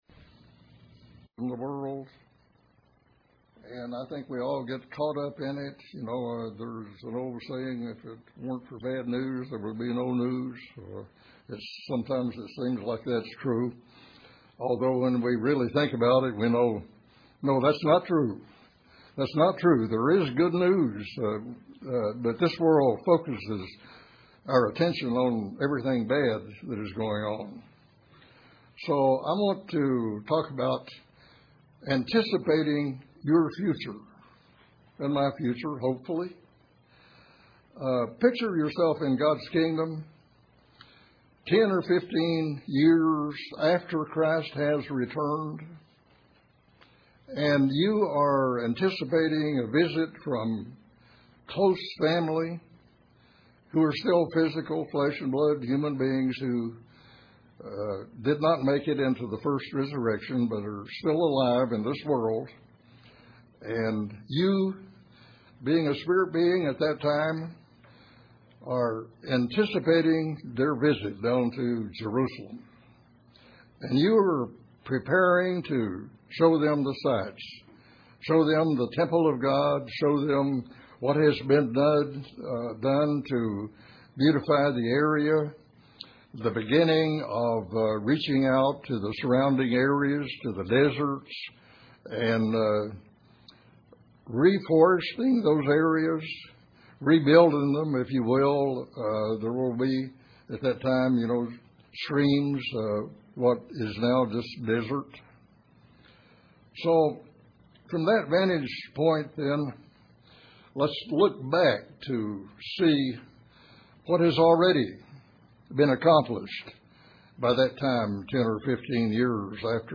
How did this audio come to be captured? Given in Paintsville, KY